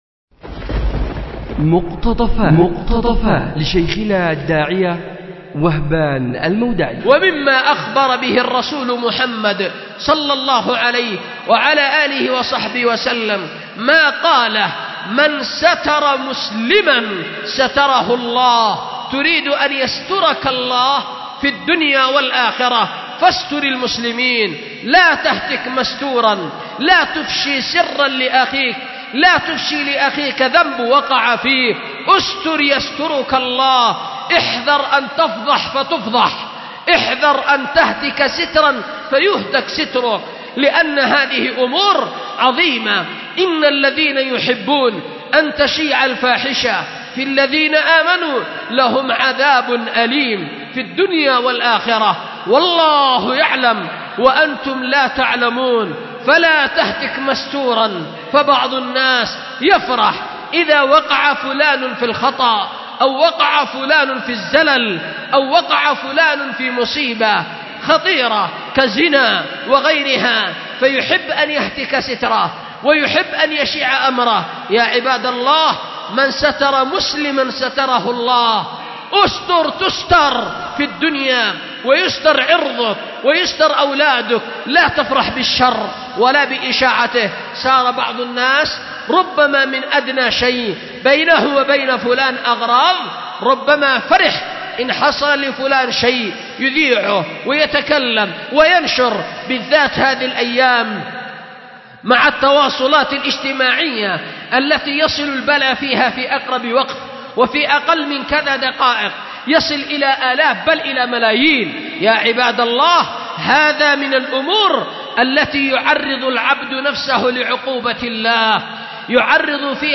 أُلقيت بدار الحديث للعلوم الشرعية بمسجد ذي النورين ـ اليمن ـ ذمار